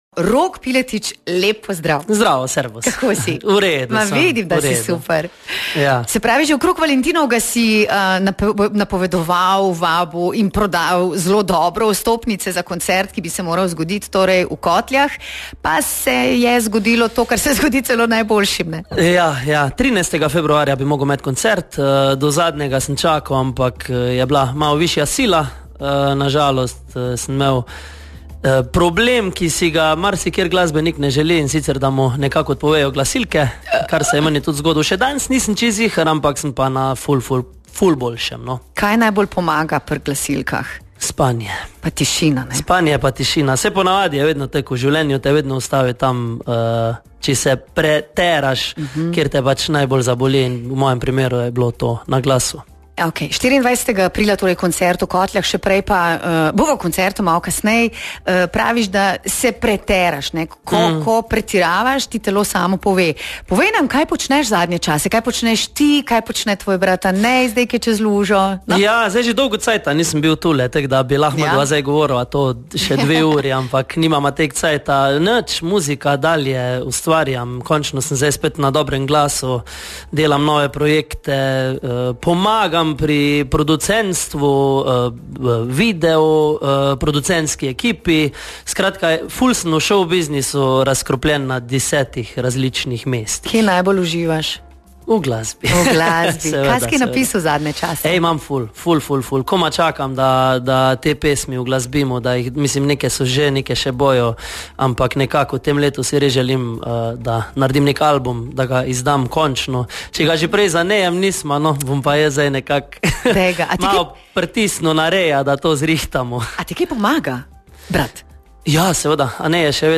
Zakaj je najtežje nastopati doma in ali bo tudi tokrat poskrbel za presenečenje na odru? Poslušajte cel intervju.